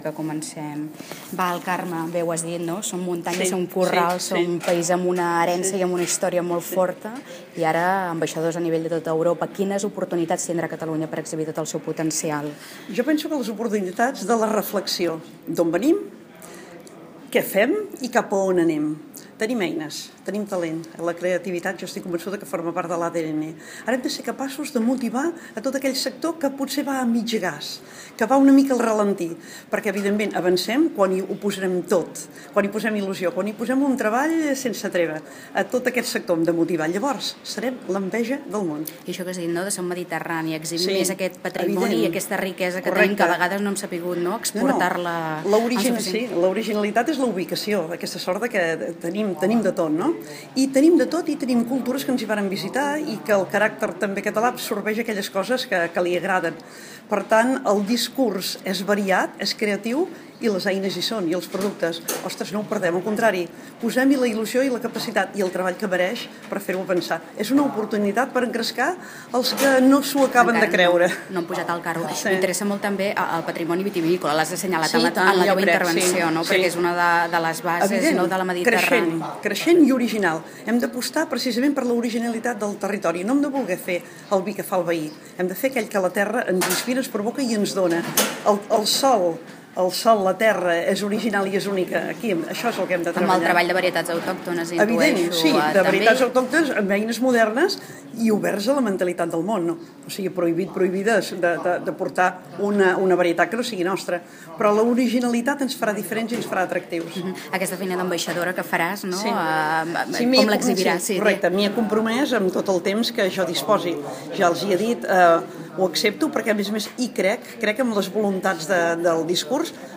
ENTREVISTA A LA XEF